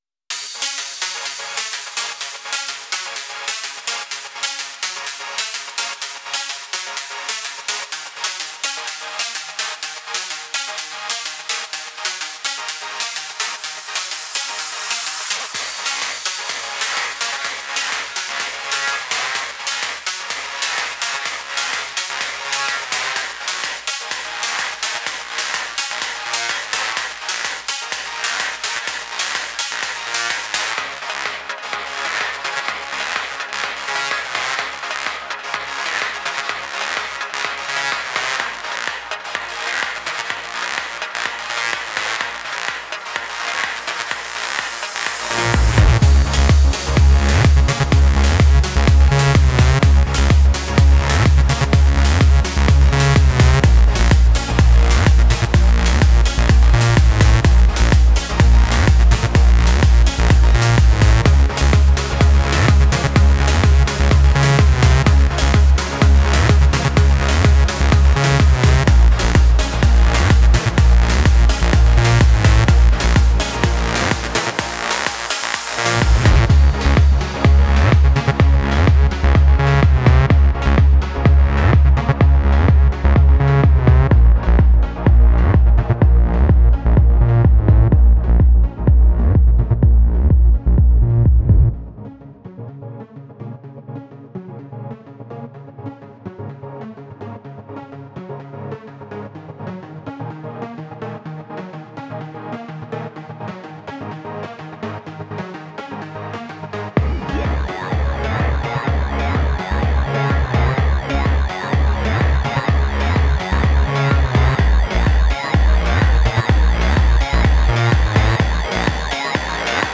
ELECTRO S-Z (34)